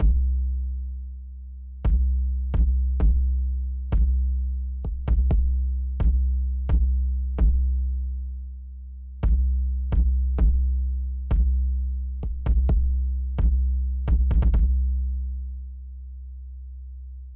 motorsport_Kick.wav